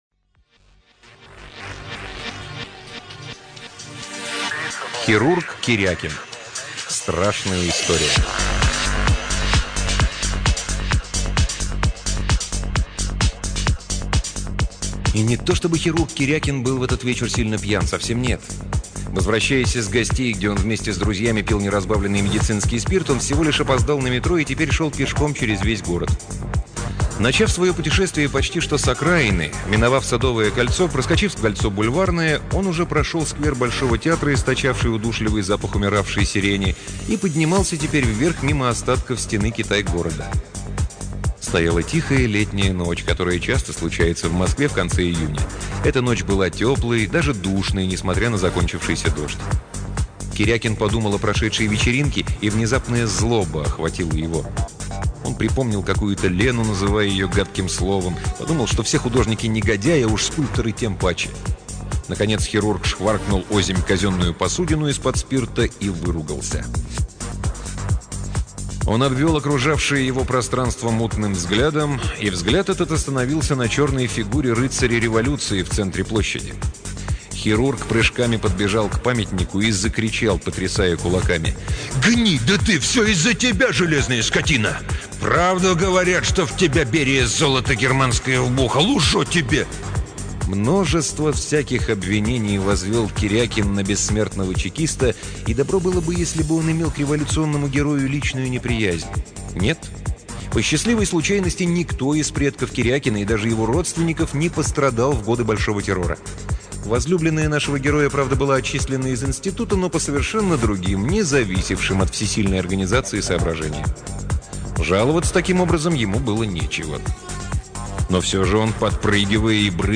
Аудиокнига Владимир Березин — Хирург Кирякин